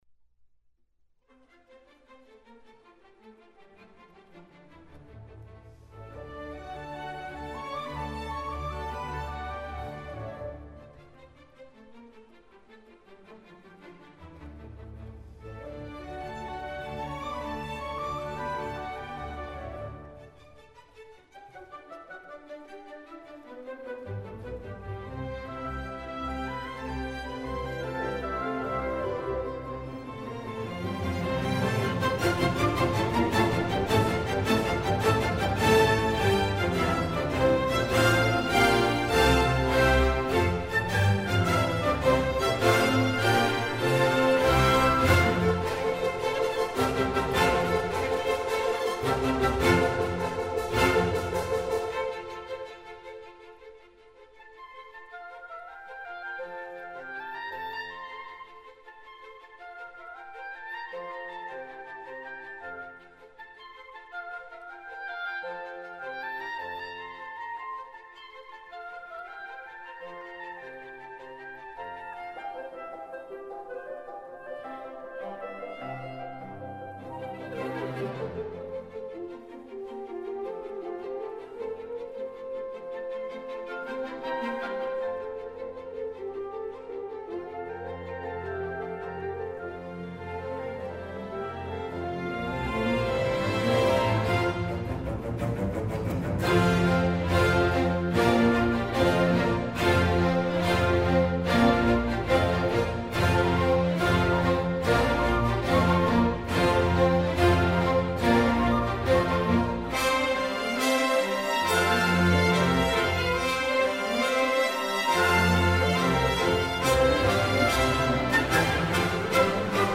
So multi-faceted are the shades of colour they bring alive.